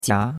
jia2.mp3